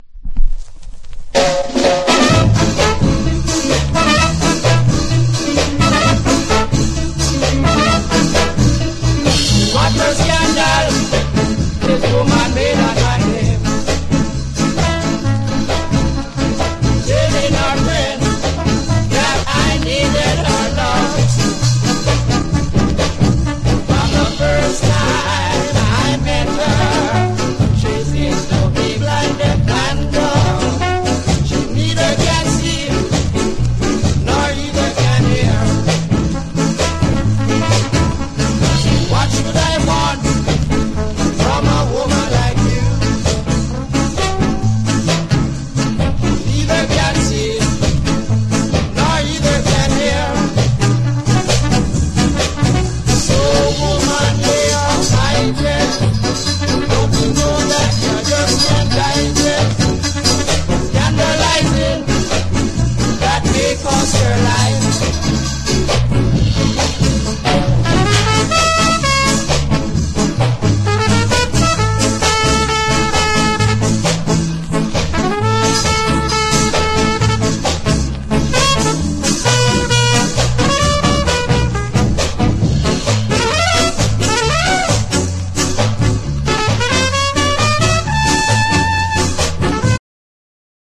# SKA / ROCK STEADY